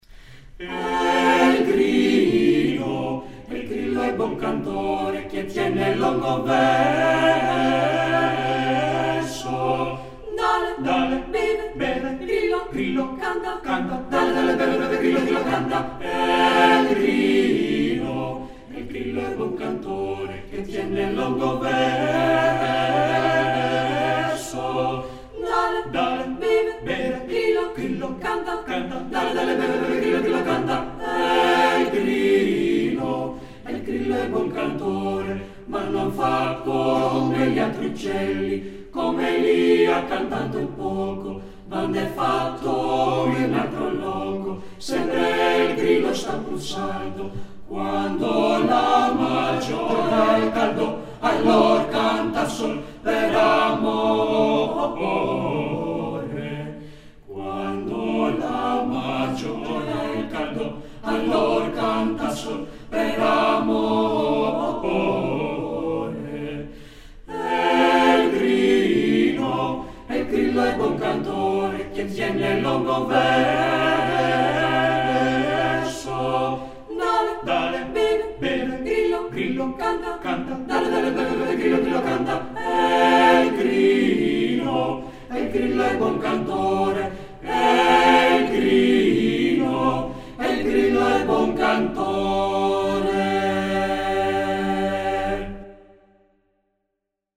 eq = Equalizer, rev = reverb